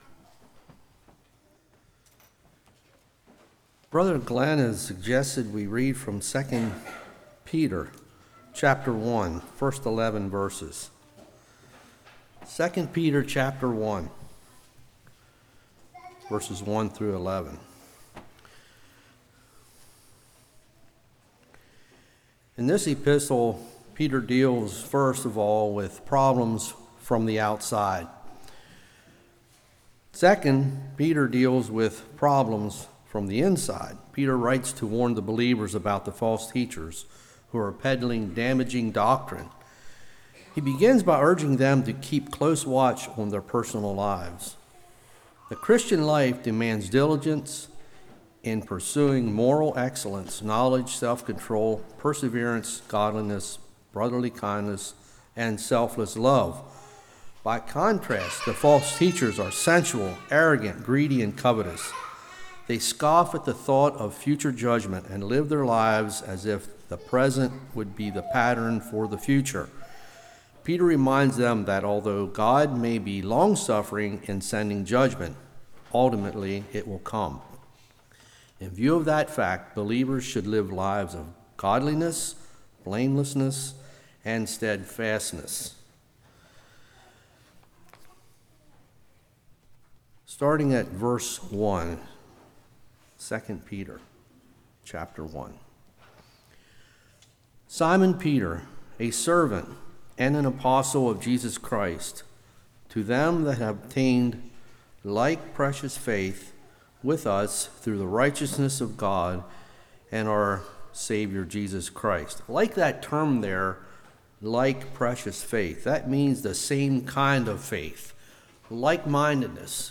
2 Peter 1:1-11 Service Type: Evening Virtue